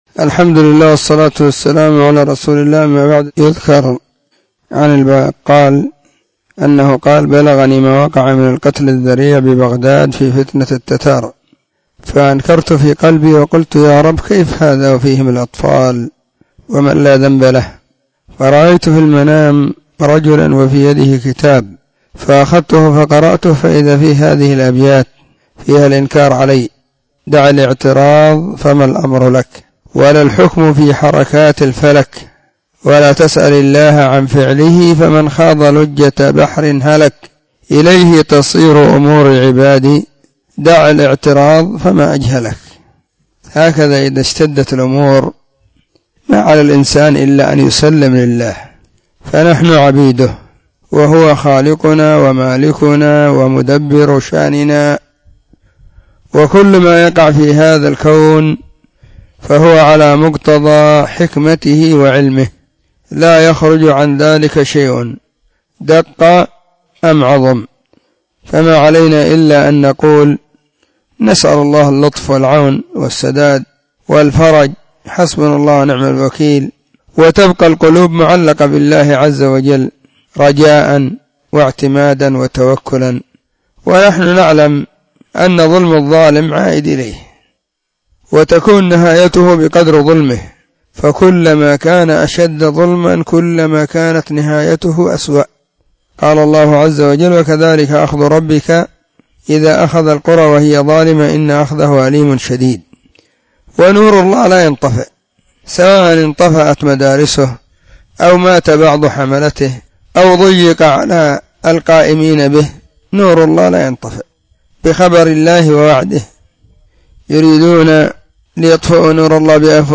📢 مسجد – الصحابة – بالغيضة – المهرة، اليمن حرسها الله.